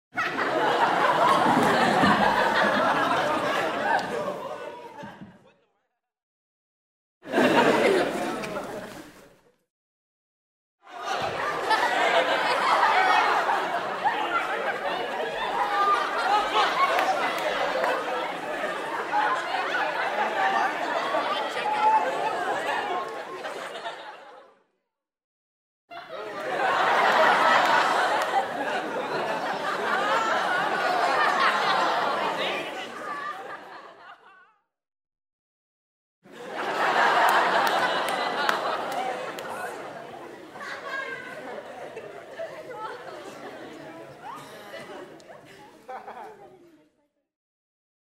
Подростковый смех в закрытом пространстве